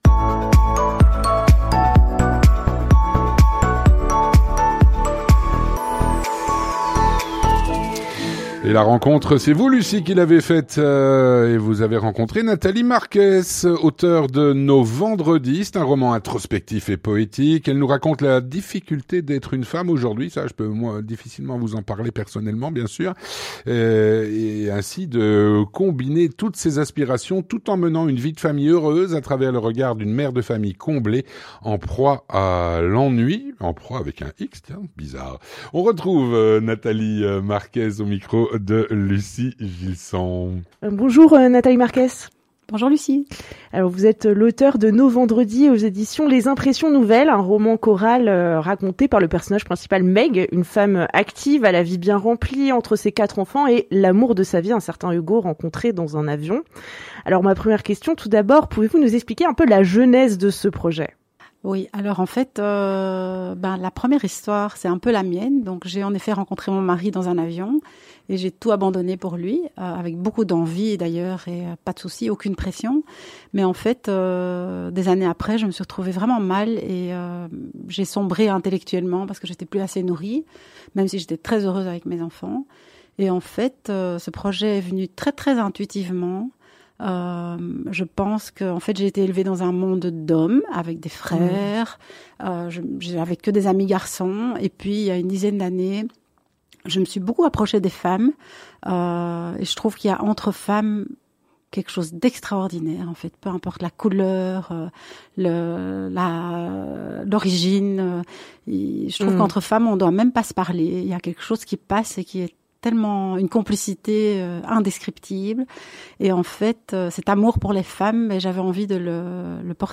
Rencontre - “Nos Vendredis”, un roman introspectif et poétique.